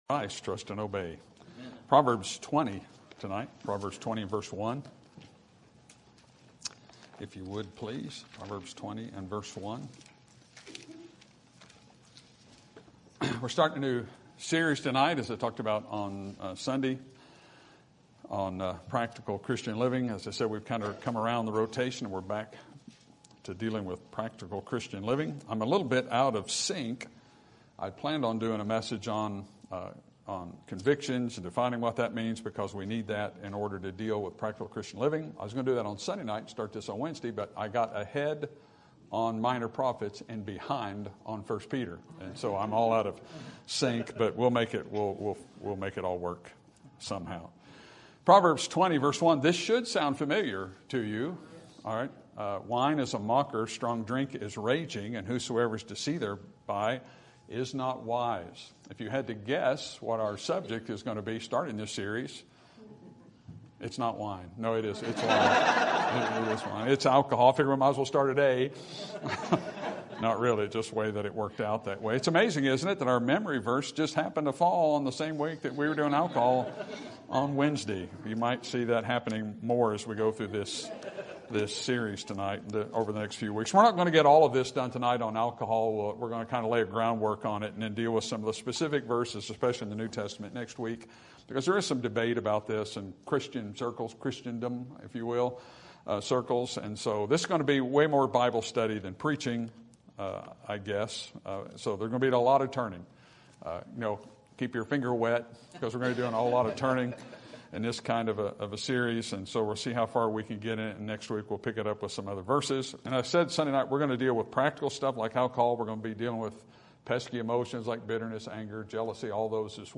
Sermon Topic: Practical Christian Living Sermon Type: Series Sermon Audio: Sermon download: Download (29.92 MB) Sermon Tags: Proverbs Wine Leaven Drinking